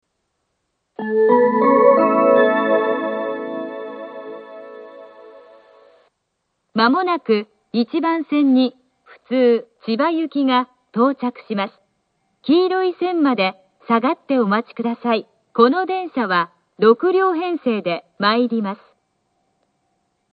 １番線接近放送 普通千葉行（６両）の放送です。